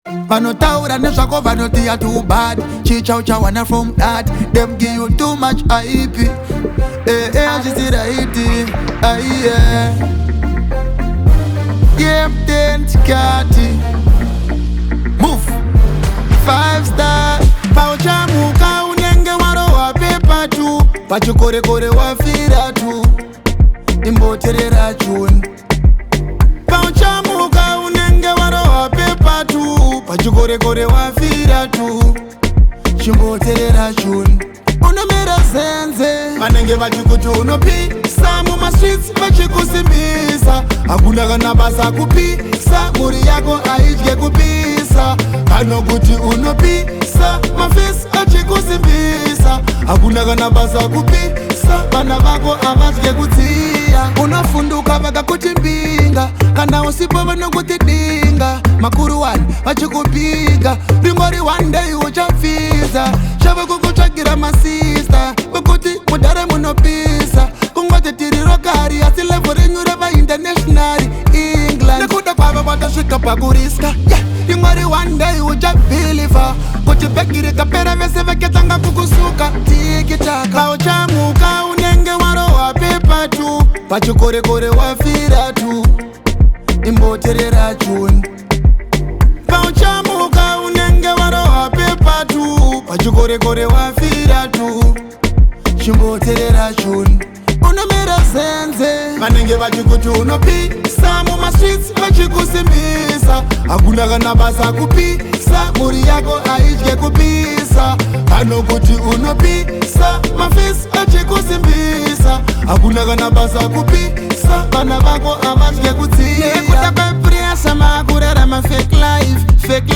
smooth vocals
blends Afrobeat with contemporary sounds
With its high energy tempo and catchy sounds